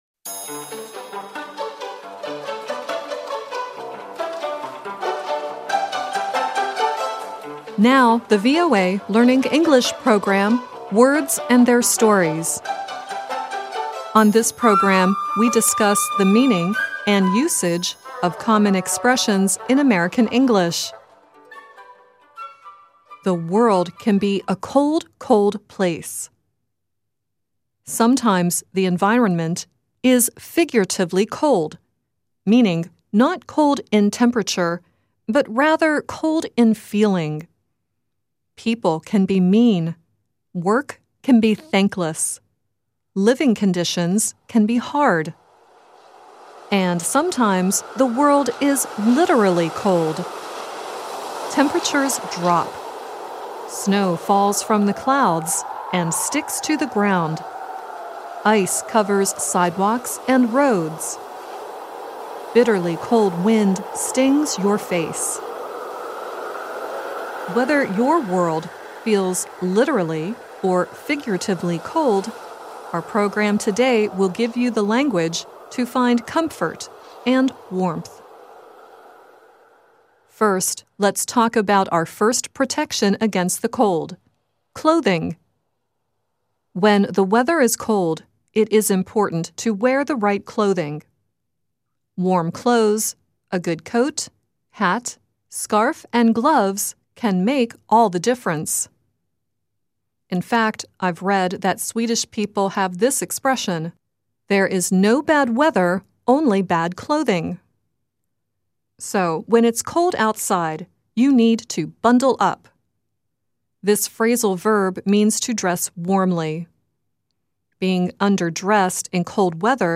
The song at the end is Billy Gilman singing "Warm and Fuzzy."